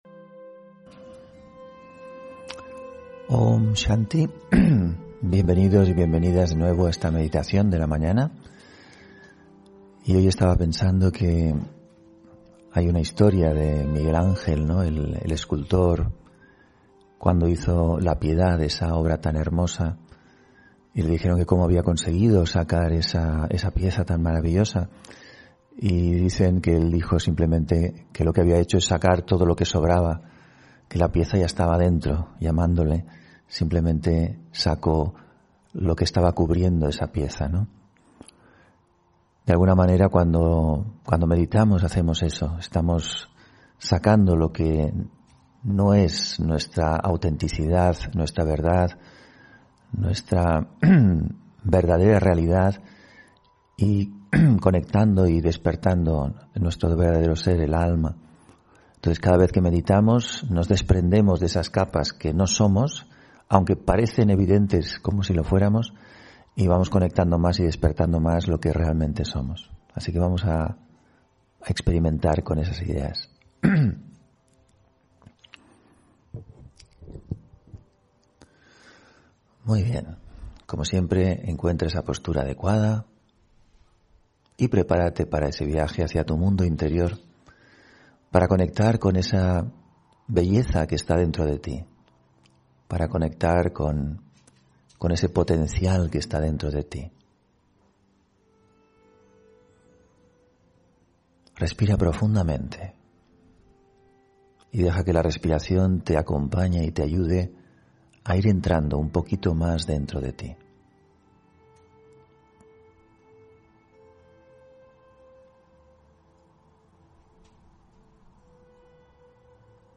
Audio conferencias
Meditación de la mañana